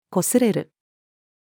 擦れる-to-be-rubbed-female.mp3